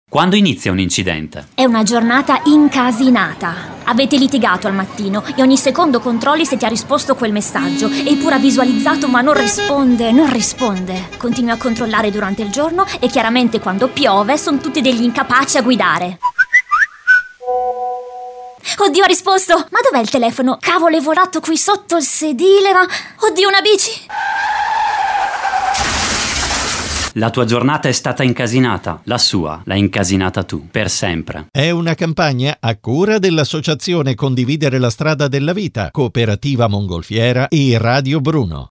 Continuate a seguirci sui nostri social, per scoprire tutti gli spot radio della campagna “Quando inizia un incidente?”